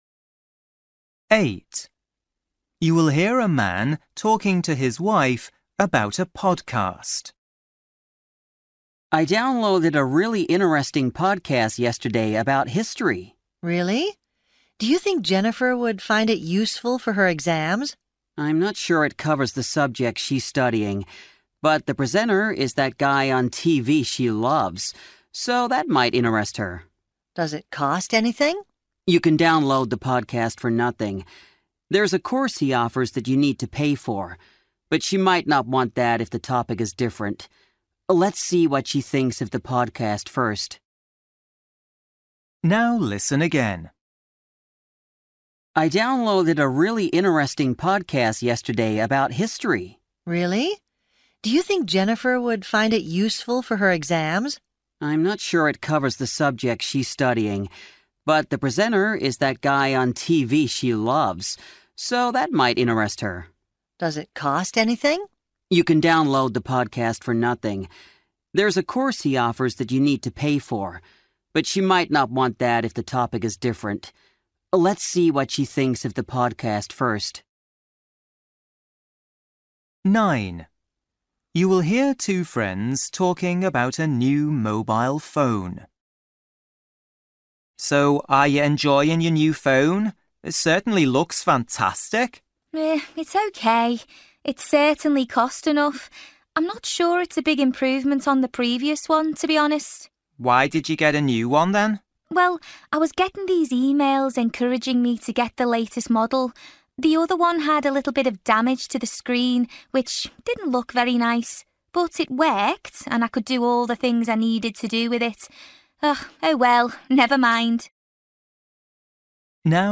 Listening: everyday short conversations
You will hear a man talking to his wife about a podcast. The man thinks
You will hear two friends talking about a new mobile phone. The woman says
11   You will hear two friends talking about living in the countryside. What does the woman say?